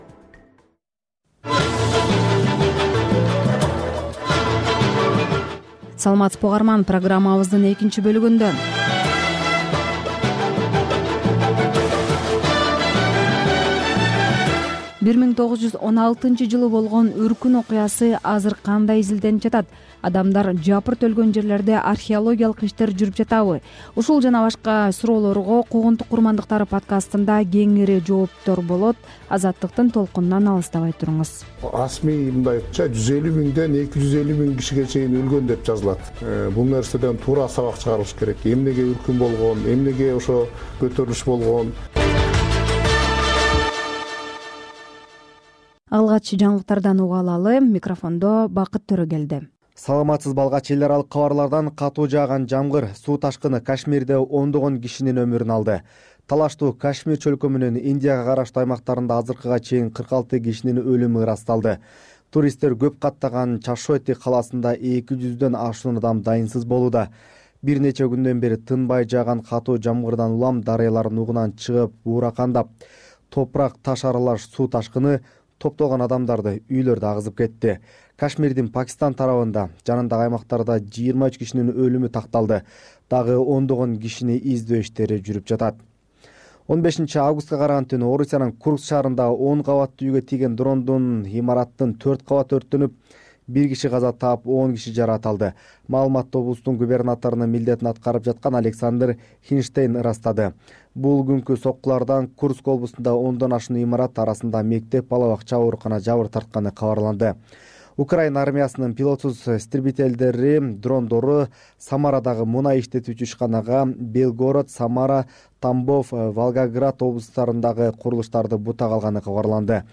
Бул үналгы берүү ар күнү Бишкек убакыты боюнча саат 18:30дан 19:00гө чейин обого түз чыгат.